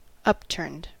Ääntäminen
Ääntäminen US Haettu sana löytyi näillä lähdekielillä: englanti Upturned on sanan upturn partisiipin perfekti.